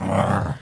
动物 西施犬 " 狗狗西施犬吠声单曲02
描述：西施犬，单树皮
标签： 咆哮 动物 西施姿 树皮
声道立体声